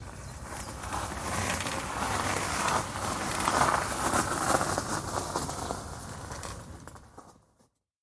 По камням на велосипеде